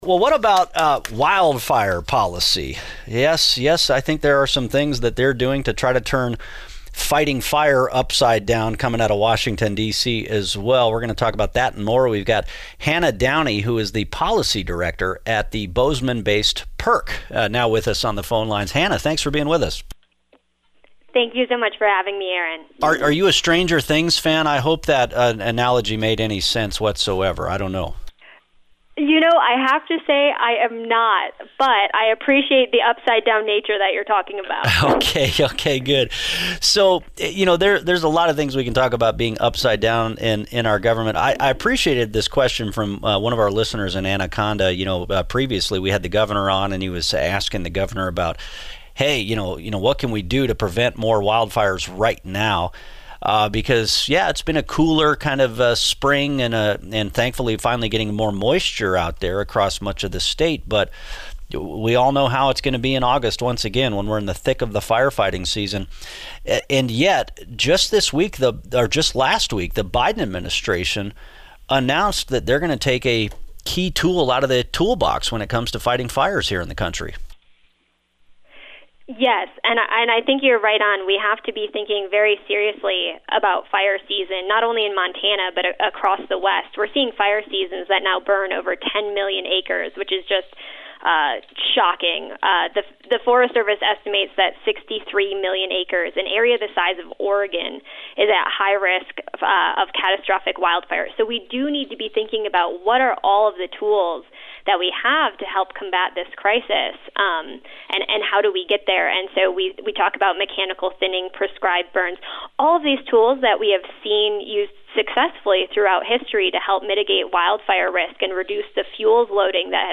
on Montana Talks radio program